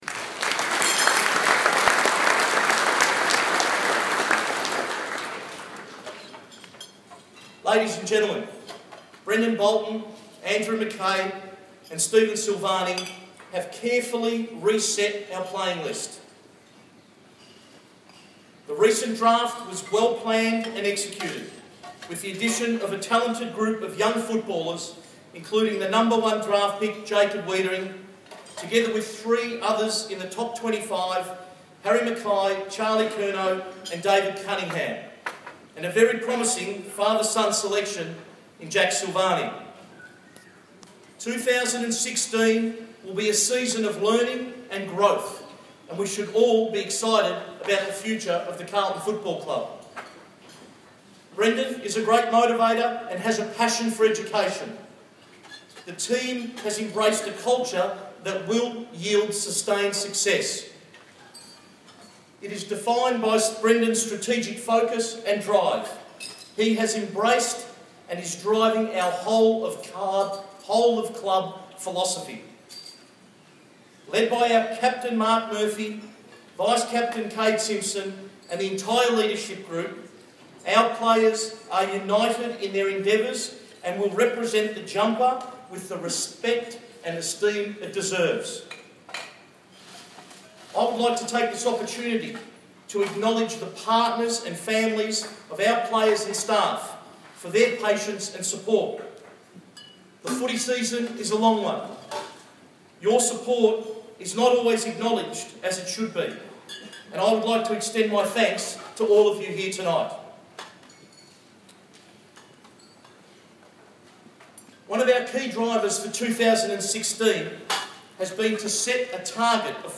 2016 Season Launch - President's speech